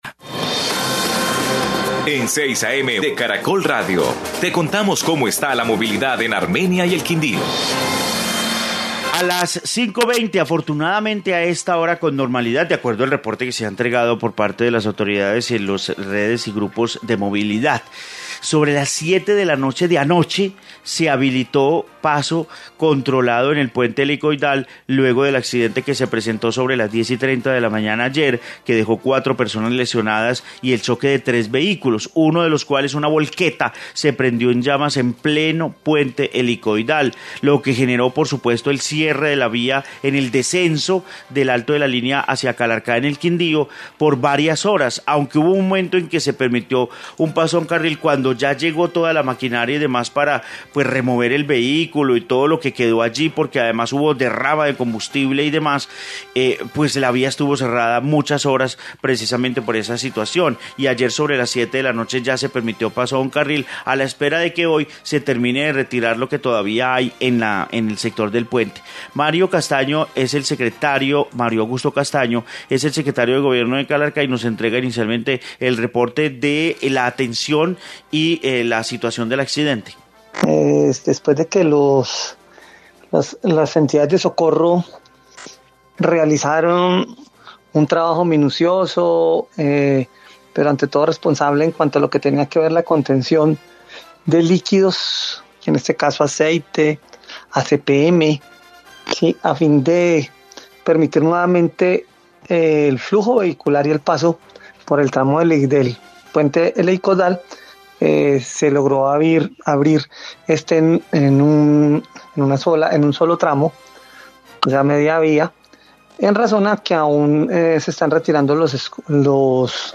Informe puente Helicoidal en Calarcá, Quindío